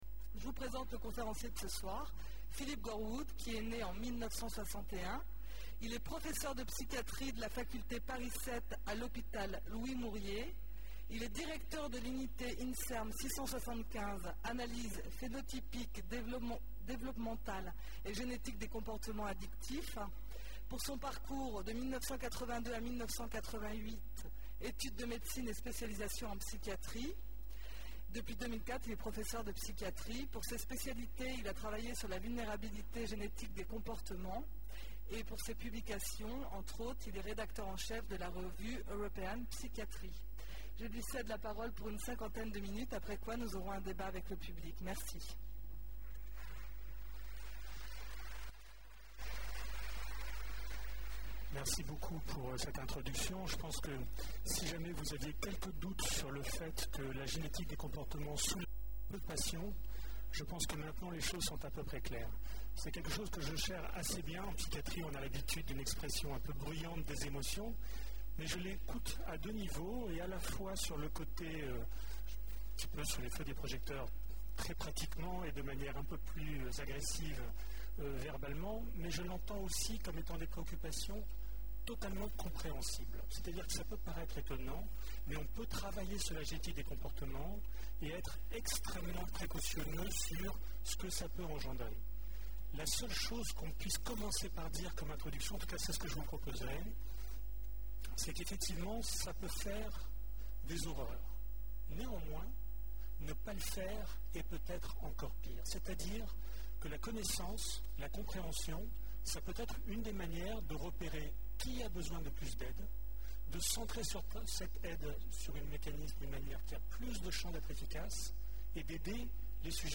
Une conférence du cycle : Qu'est ce que la vie ? Où en est la connaissance du génome ?
Hôpital Louis Mourier (La conférence a été interrompue brutalement en raison d’une coupure de courant – l’exposé du conférencier n’a pu être filmé dans sa totalité )